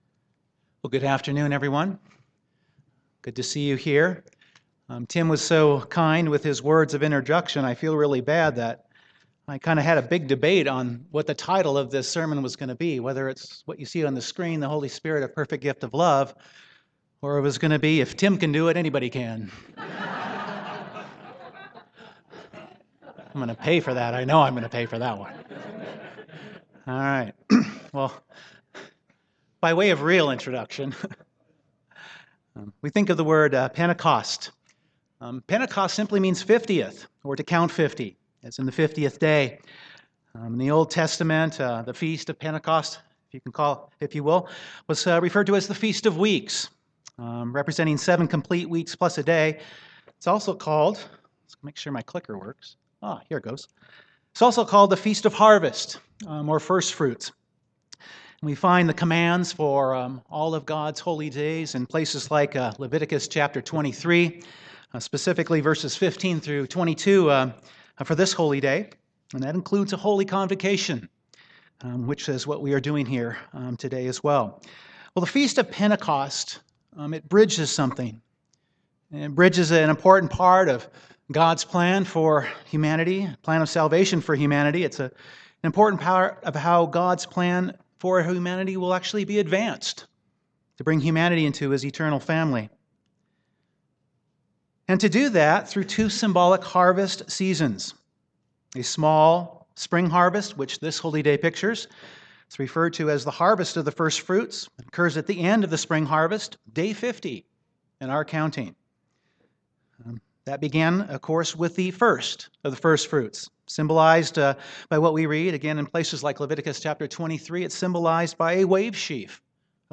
On Pentecost (Acts 2) the Holy Spirit came as a rushing wind; power was given, prophecy was fulfilled. This sermon centers on the gift of the Holy Spirit as part of God’s plan of salvation, a focus on the need for the Holy Spirit to know and understand God - as God is love (1 John 4:8) - and how this love is evidenced with the Holy Spirit working in us.